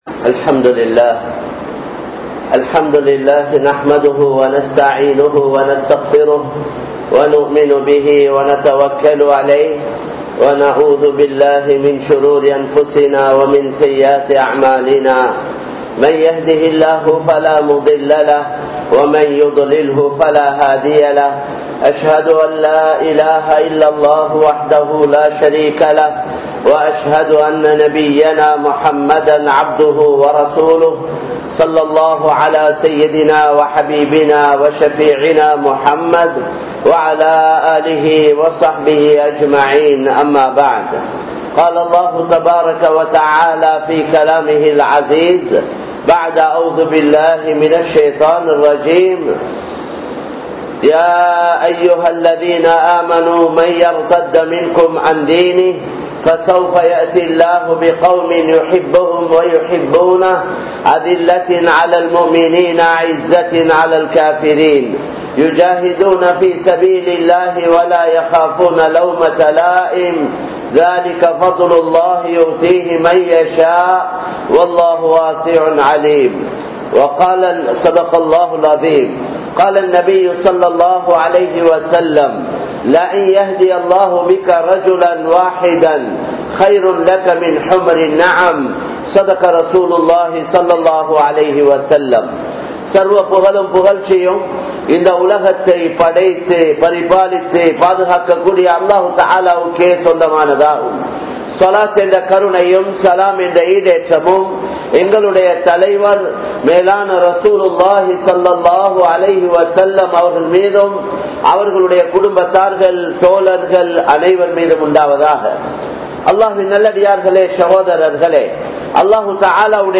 சமூகத்தின் மீது நமது பொறுப்பு | Audio Bayans | All Ceylon Muslim Youth Community | Addalaichenai